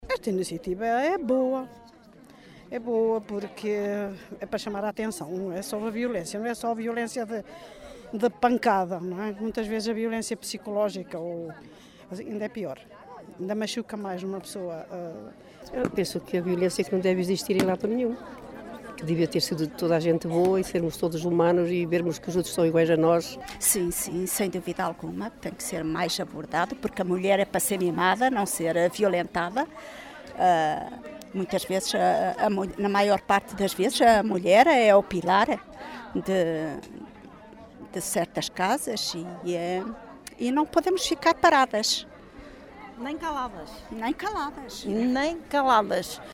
Entre os participantes, muitas mulheres.
vox-pop.mp3